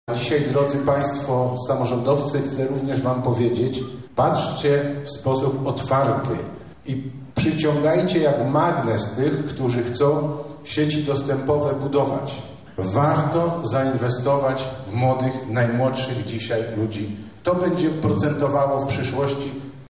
Wczoraj odbyła się gala zamykająca projekt. W czasie swojego przemówienia marszałek województwa, Sławomir Sosnowski, zachęcał samorządowców do dalszych działań: